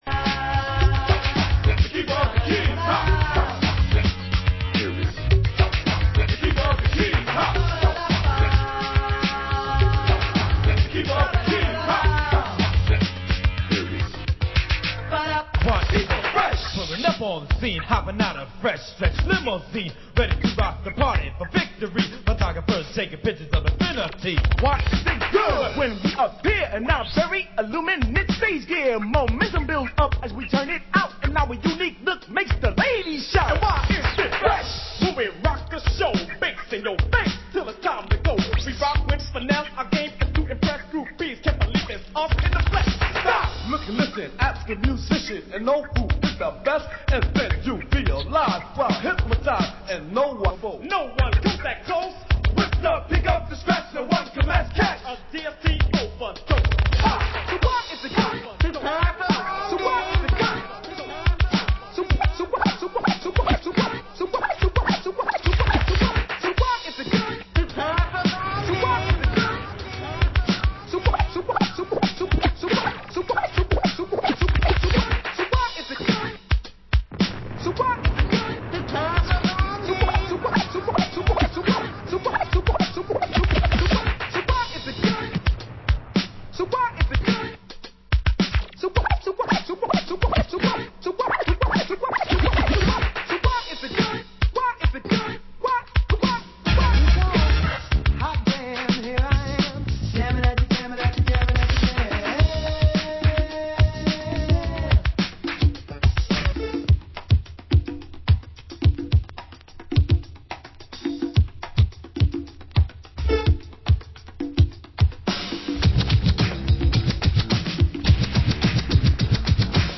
Genre: Old Skool Electro